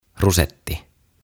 Tuhat sanaa suomeksi - Ääntämisohjeet - Sivu 55